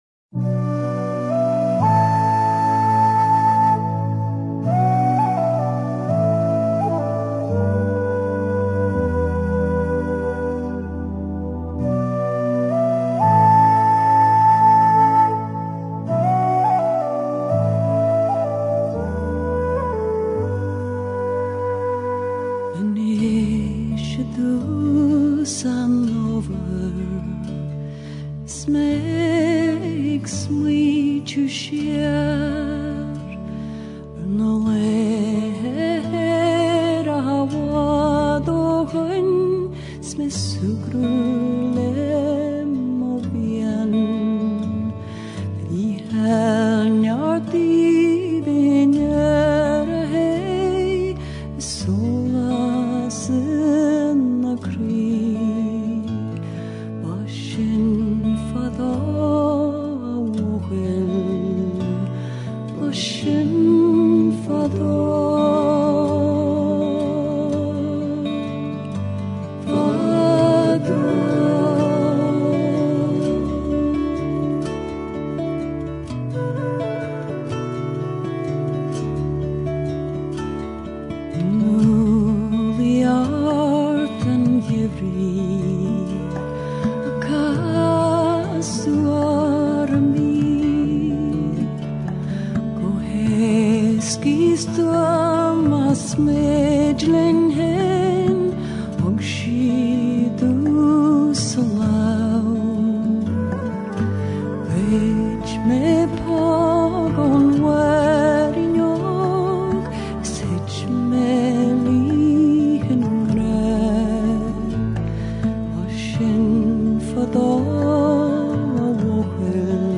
一种混合了爱尔兰民歌、凯尔特节拍以及新世纪精髓的音乐。